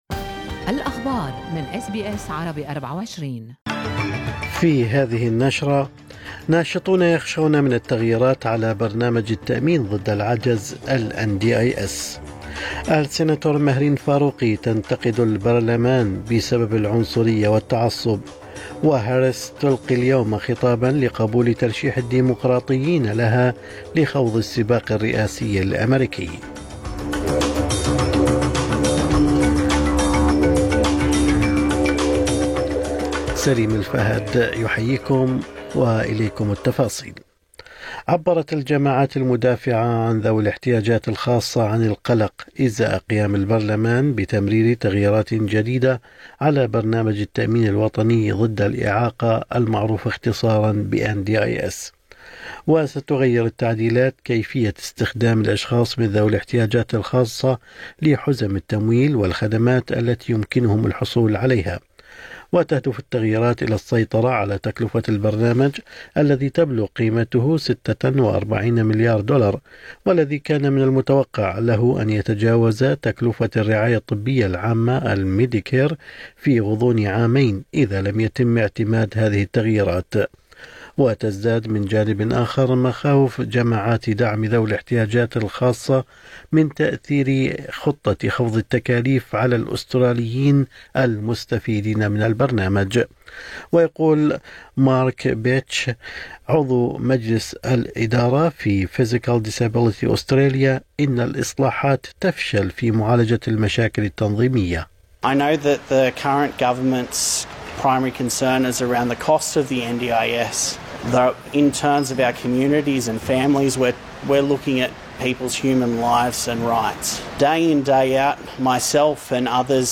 نشرة أخبار الصباح 23/8/2024